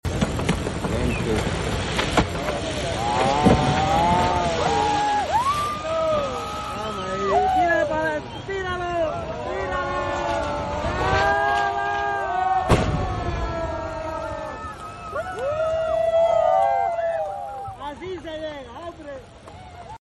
A TBM breakthrough in HRBT sound effects free download